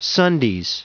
Prononciation du mot : sundays